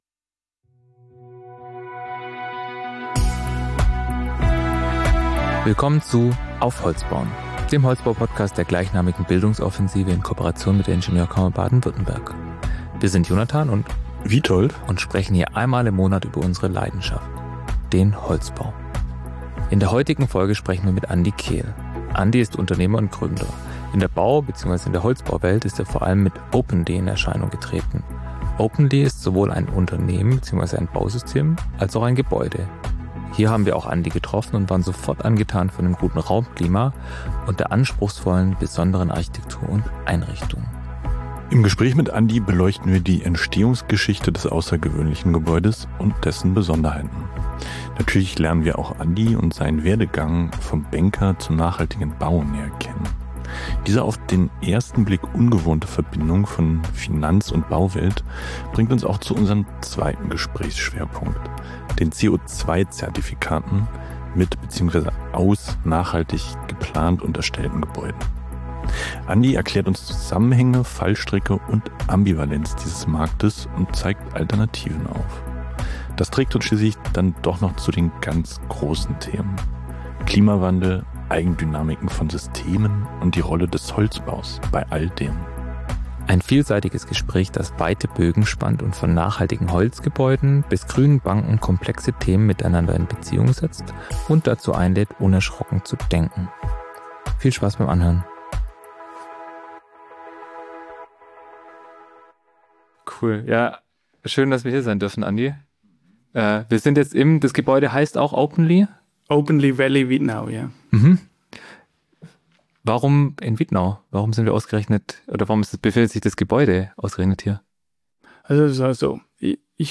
Ein vielseitiges Gespräch, das weite Bögen spannt und von nachhaltigen Holzgebäuden bis grünen Banken komplexe Themen miteinander in Beziehung setzt und dazu einlädt unerschrocken zu denken.